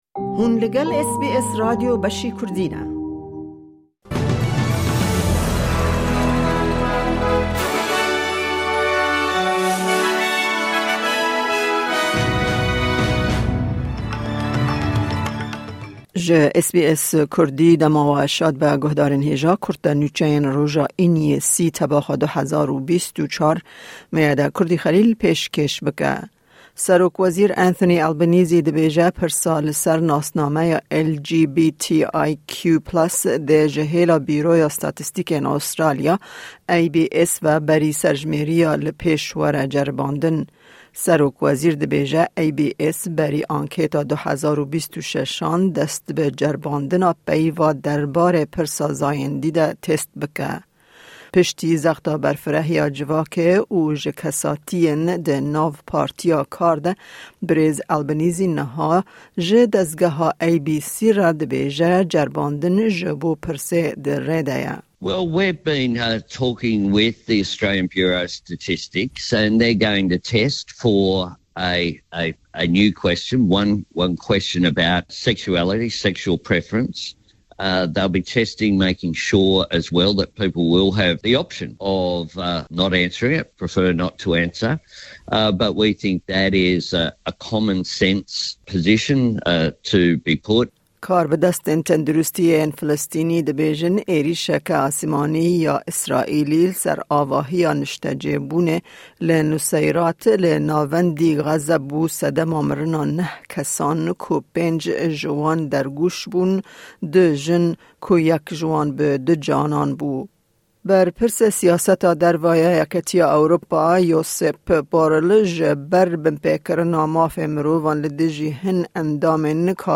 Kurte Nûçeyên roja Înî 30î Tebaxa 2024